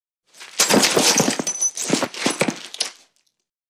| تأثير الصوت. mp3 | حمل مجانا.
رمي العنصر في سلة المهملات.: